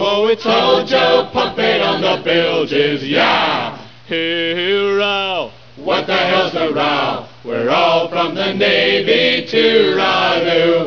vocals, violin
vocals, bodhran
-- vocals, guitar, pennywhistle
vocals, octave mandolin, hammered dulcimer